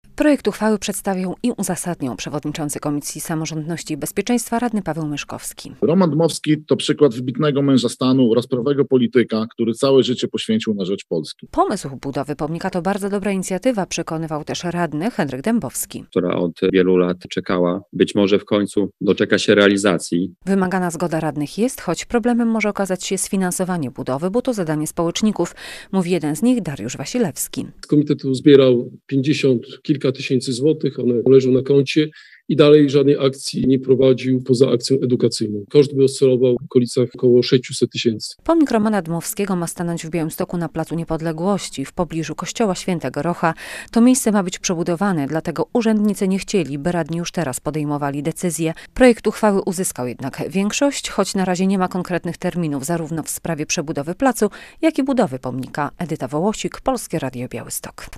Pomysł budowy pomnika to bardzo dobra inicjatywa - dodawał radny Henryk Dębowski: "która od wielu lat czekała i być może w końcu doczeka się realizacji".